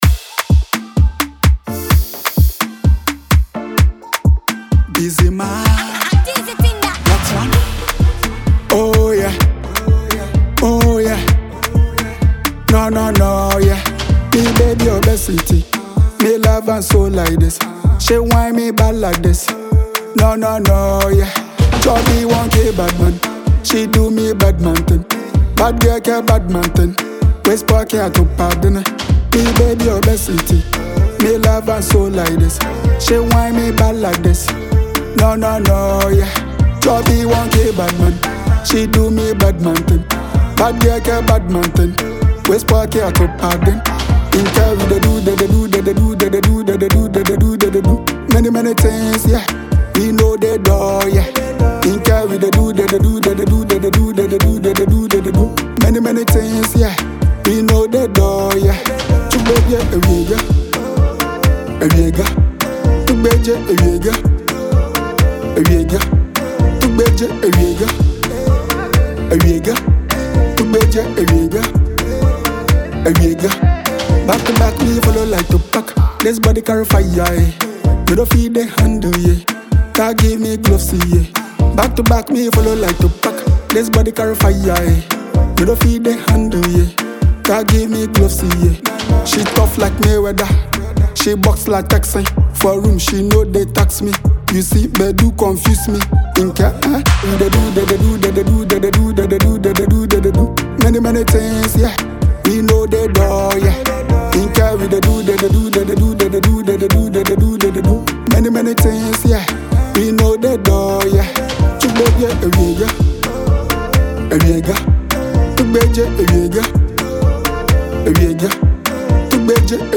banger